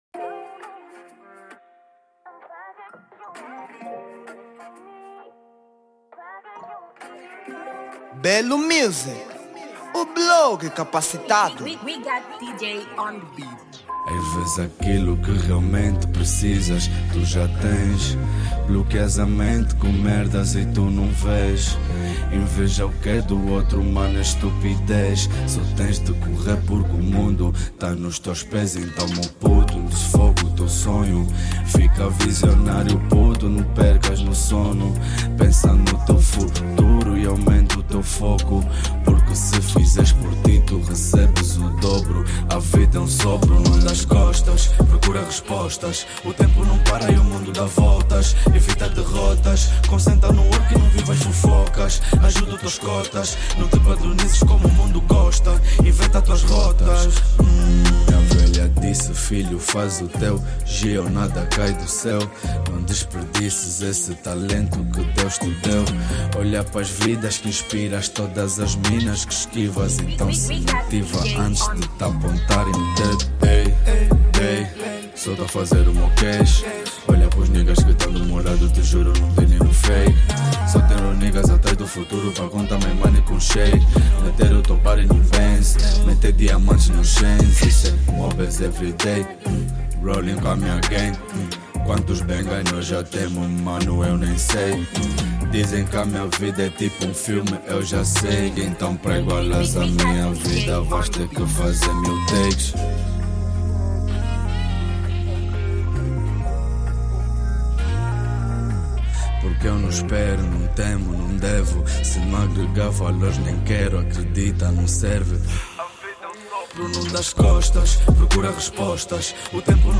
Rap Formato